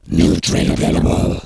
alien_newtrait2.wav